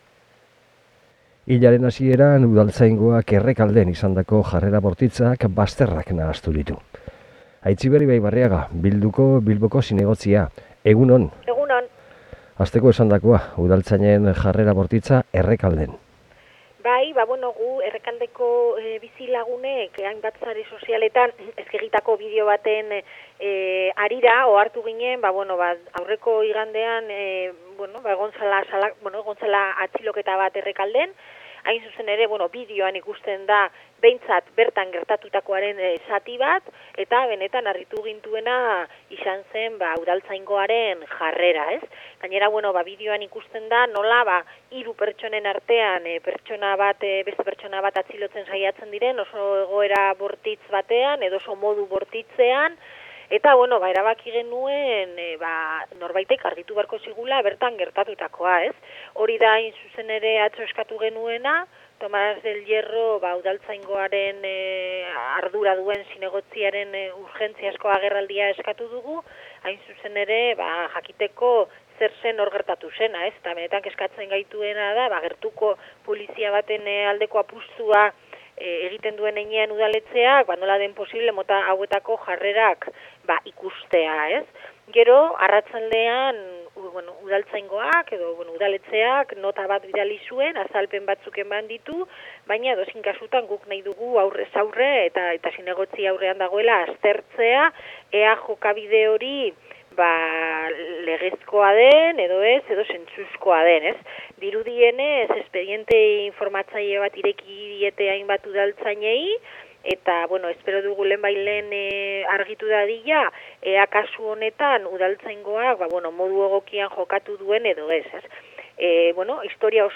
Hilaren hasieran gertatu zen, Errekalden, lagun bat atxilotzean eta Facebooken zabaldutako bideoak bazterrak nahastu ditu. Horrez gain, Aitziber Ibaibarriaga zinegotziak beste hainbat udal gai aipatu dizkigu: Masustegin egindako bisita, Uribarriko kale batzarra, eta Mario Fernandez – Mikel Cabieces auziaren inguruko elkarretaratzea.